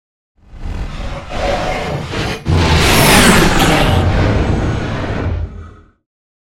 Scifi whoosh pass by long
Sound Effects
futuristic
pass by
vehicle